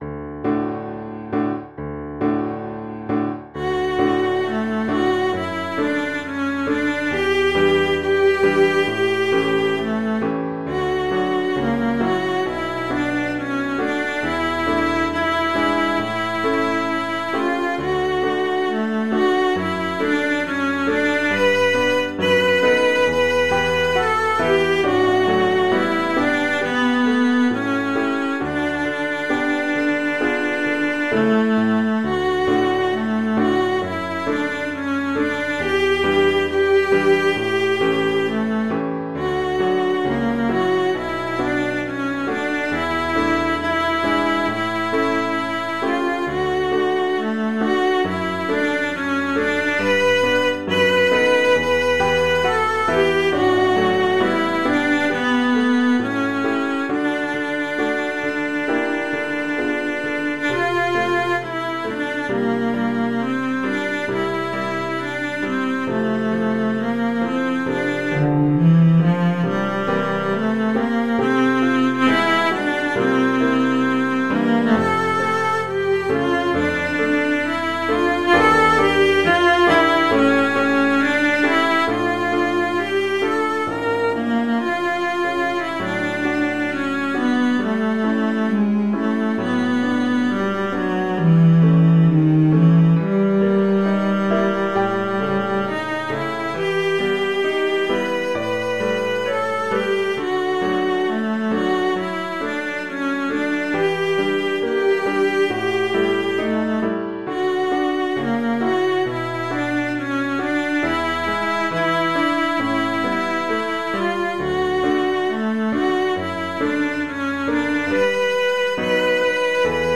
Cello Music